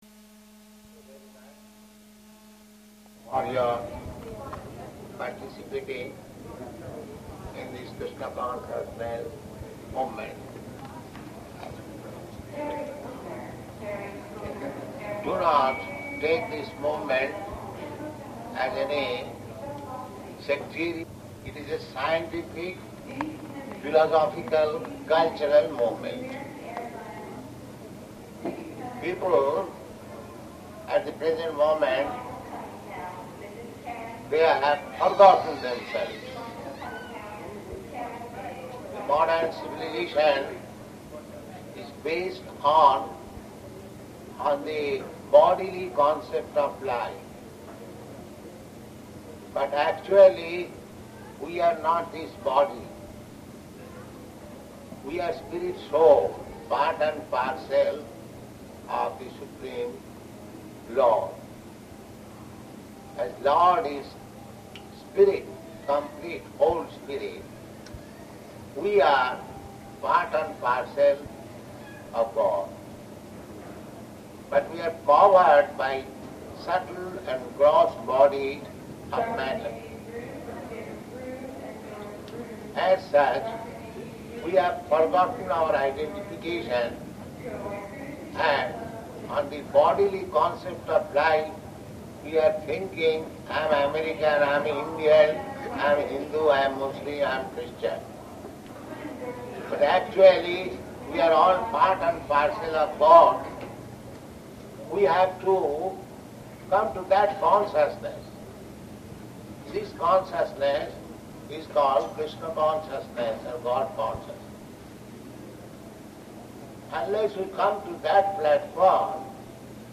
Arrival --:-- --:-- Type: Lectures and Addresses Dated: October 5th 1972 Location: Berkeley Audio file: 721005AR.BER.mp3 Prabhupāda: ...For your participating in this Kṛṣṇa consciousness movement.